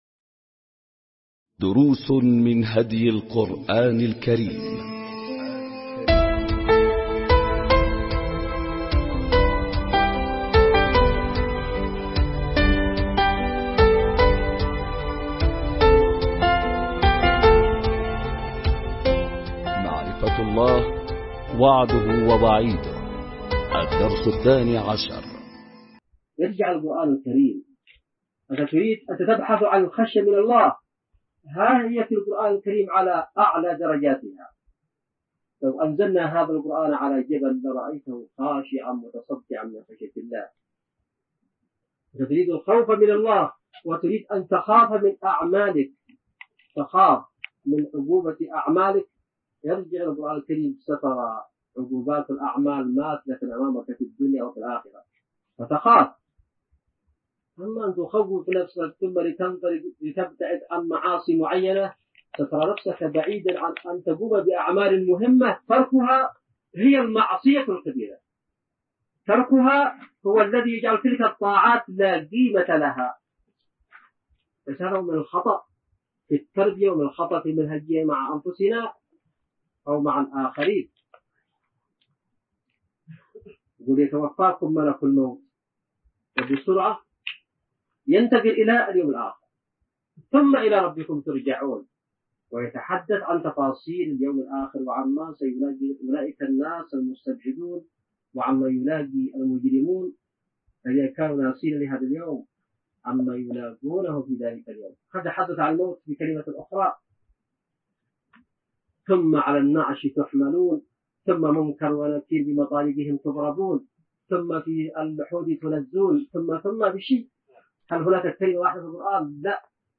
دروس من هدي القرآن الكريم معرفة الله – وعده ووعيده – الدرس الثاني عشر ملزمة الأسبوع | اليوم الرابع ألقاها السيد / حسين بدرالدين الحوثي بتاريخ 4/2/2002م